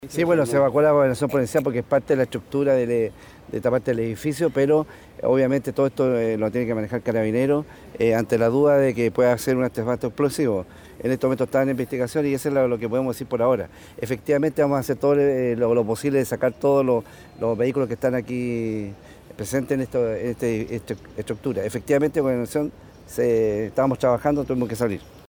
Así lo daba a conocer el gobernador de Osorno, Daniel Lilayú.